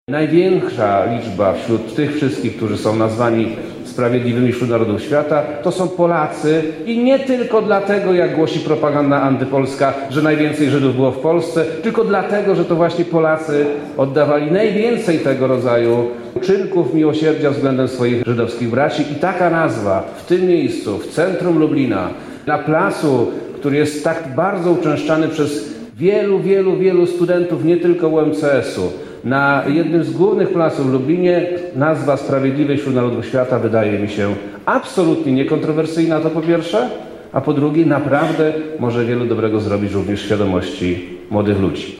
Plac będzie nosił teraz nazwę Sprawiedliwych Wśród Narodów Świata. O powodach takiej decyzji mówi wojewoda Przemysław Czarnek